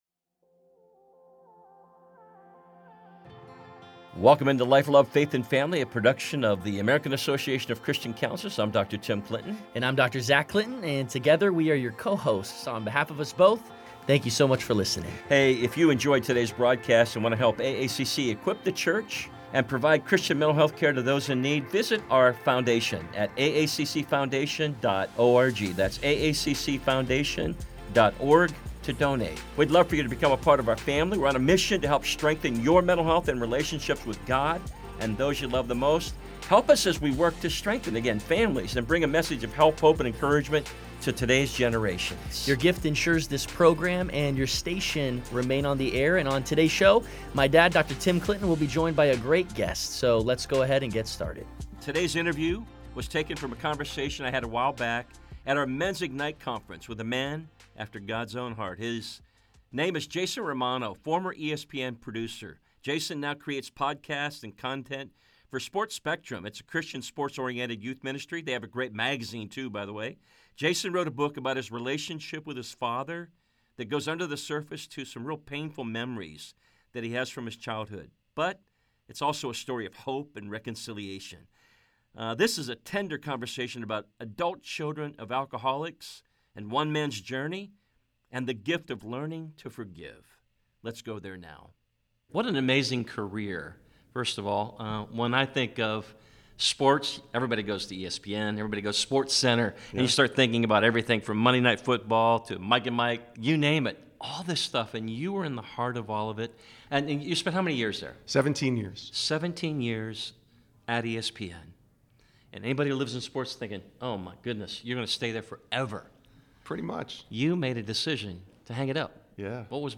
a tender conversation